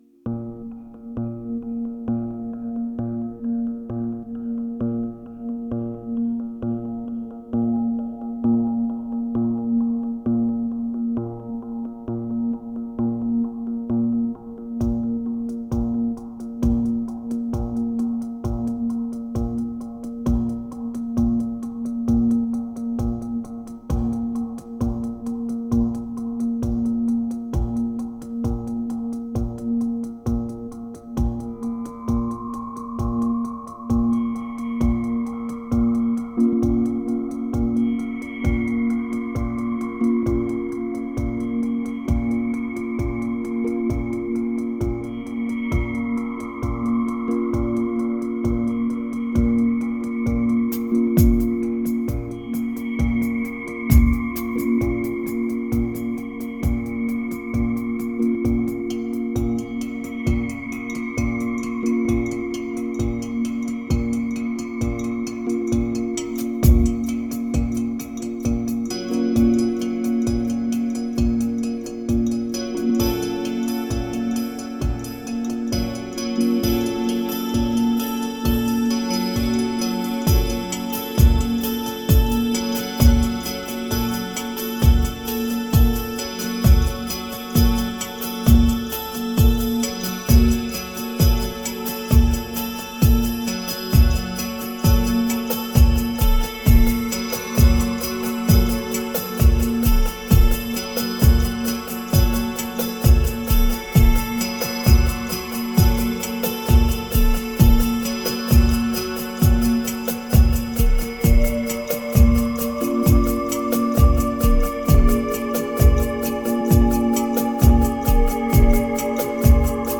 2665📈 - 74%🤔 - 66BPM🔊 - 2012-11-08📅 - 251🌟